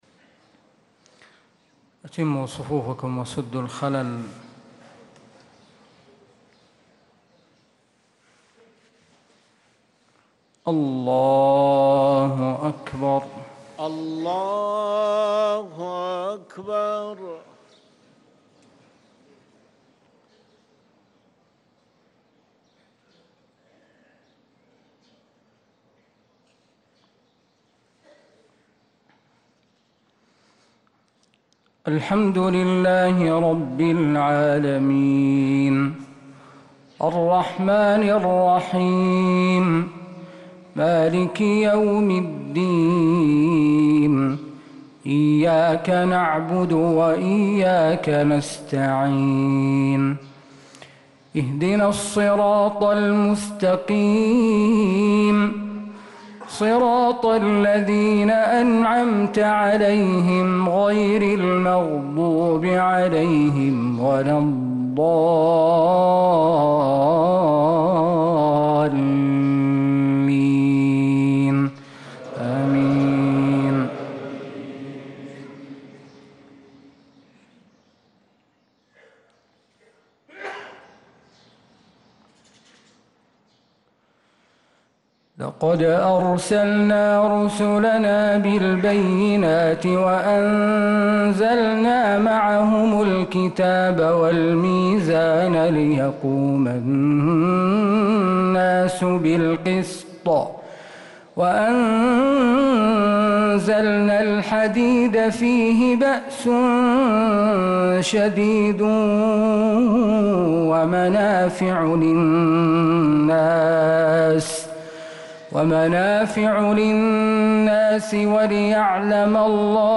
صلاة المغرب للقارئ